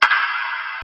TM88 LowSnare.wav